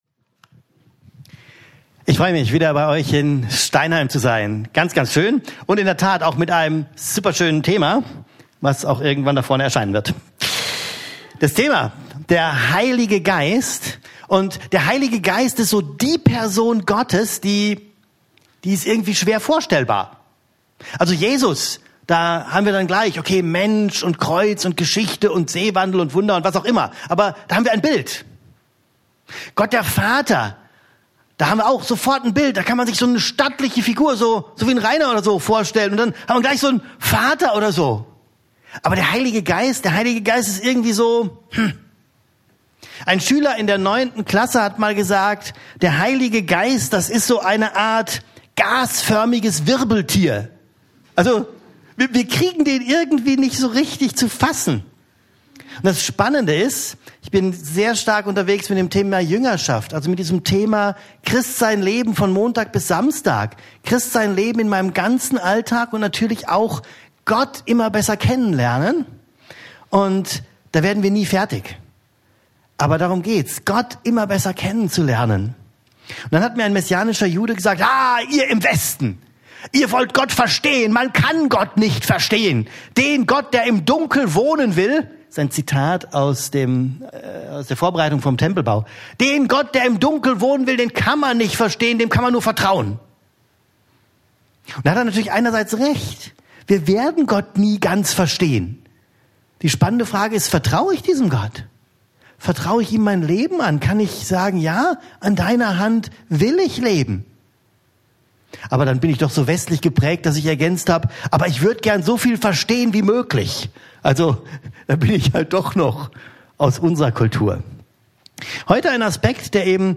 Podcast vom letzten Gottesdienst.